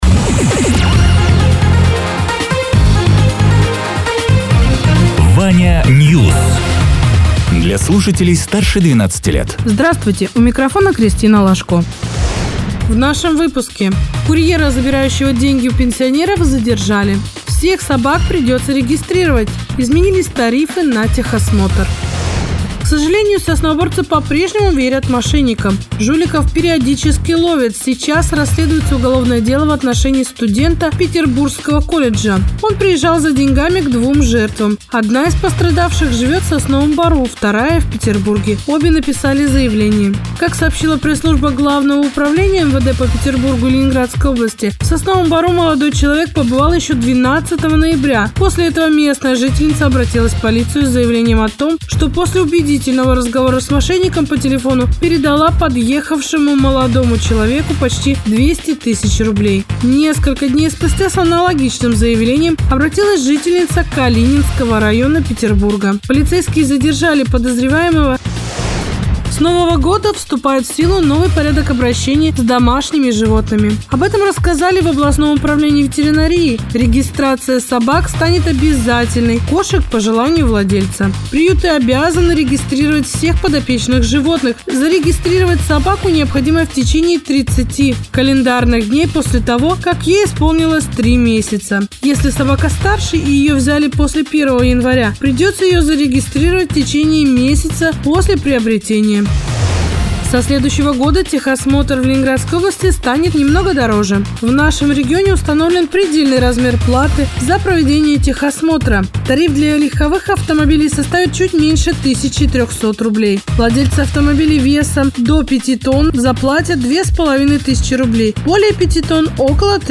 Радио ТЕРА 02.04.2026_12.00_Новости_Соснового_Бора